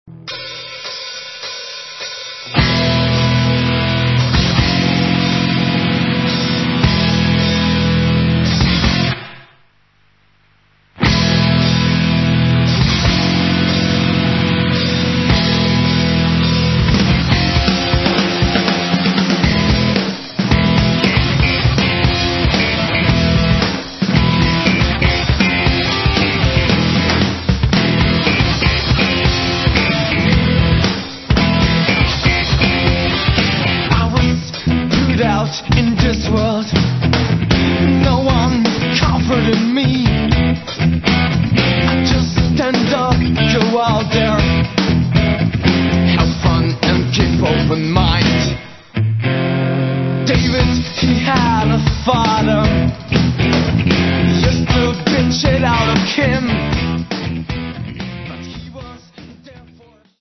Enominutni Lo-Fi MP3 izsečki za hitro predstavitev ...